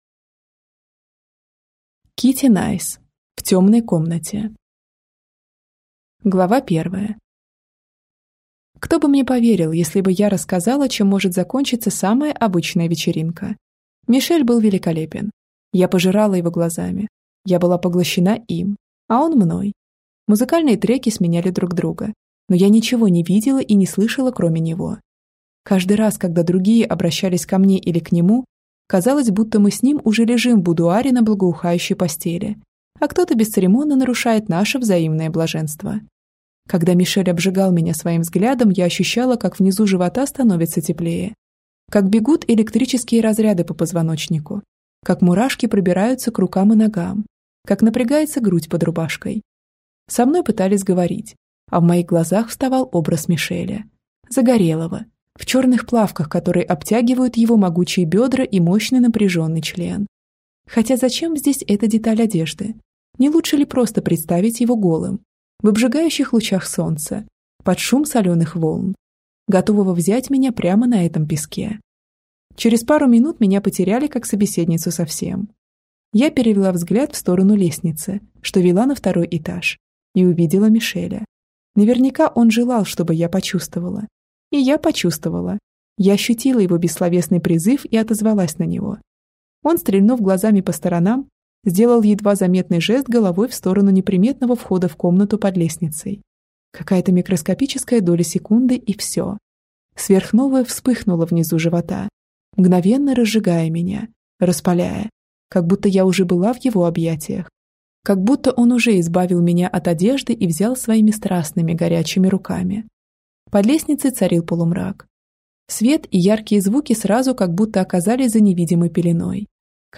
Аудиокнига В темной комнате | Библиотека аудиокниг